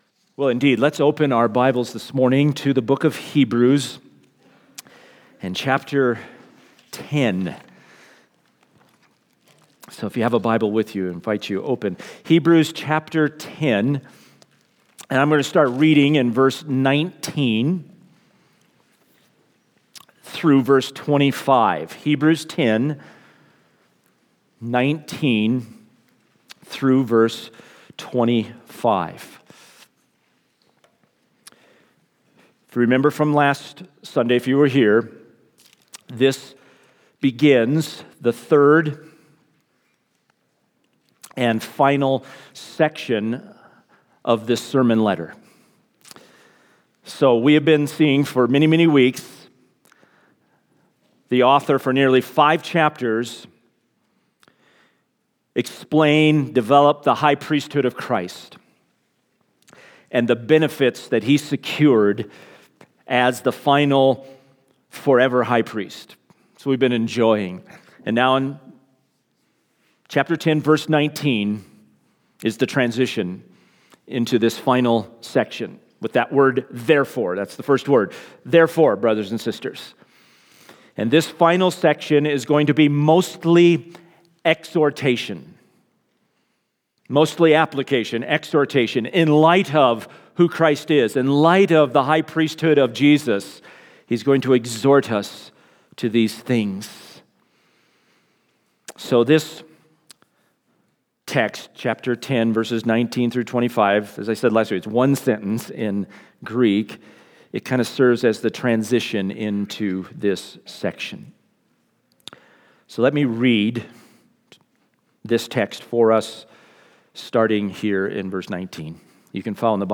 SERMON – Crossroads Bible Church